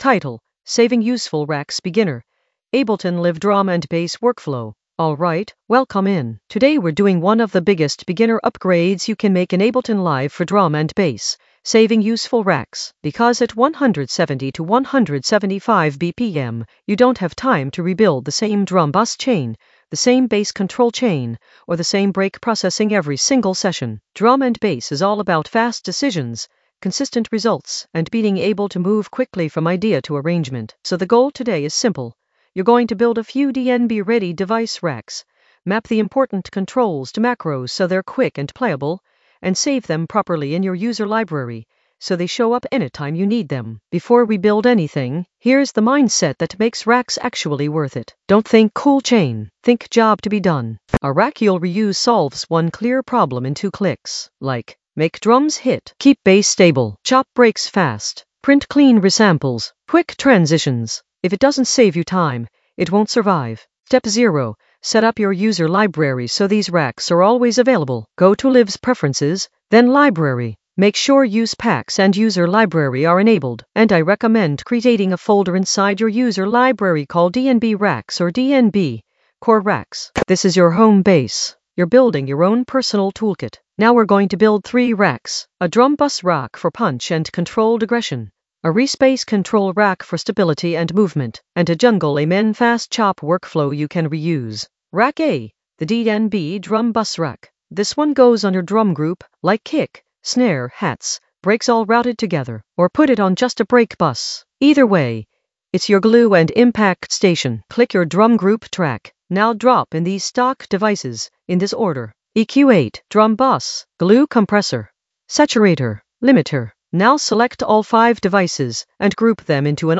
An AI-generated beginner Ableton lesson focused on Saving useful racks in the Workflow area of drum and bass production.
Narrated lesson audio
The voice track includes the tutorial plus extra teacher commentary.